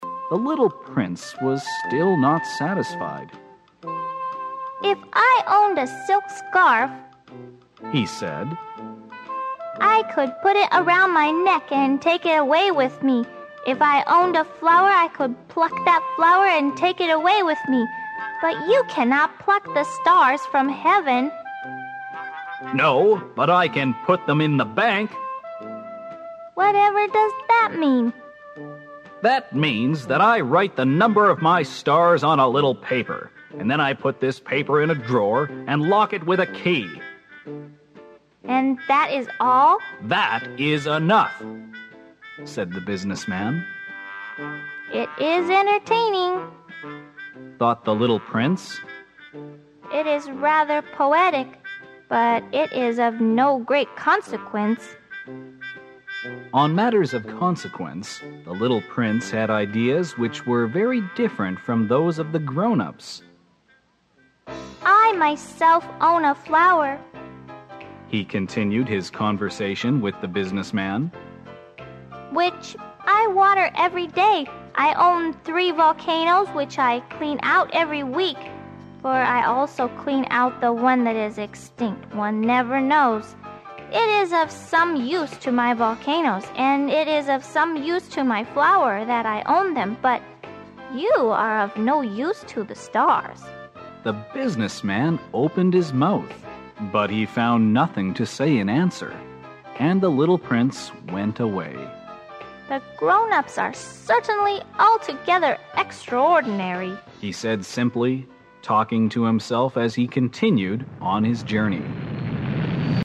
本栏目包含中英文本和音频MP3文件，让我们随着英文朗读与双语文本一起出发，重温这部经典之作，寻找灵魂深处的温暖。